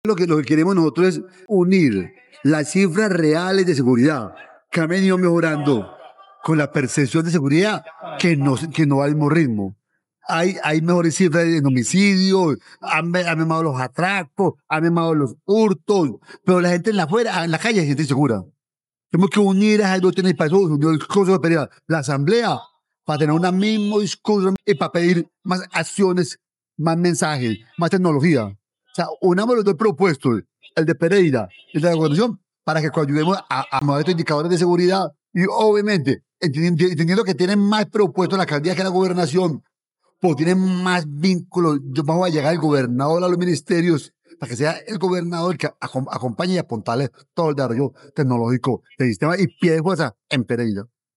En las instalaciones del Batallón de Artillería de Campaña No. 8 San Mateo se llevó a cabo una sesión conjunta y descentralizada entre la Asamblea Departamental de Risaralda y el Concejo de Pereira, un espacio institucional clave para analizar la situación de seguridad del departamento y avanzar en acciones articuladas frente a la alerta temprana 001 de 2026.
Escuchar Audio Juan Carlos Valencia, presidente de la Asamblea De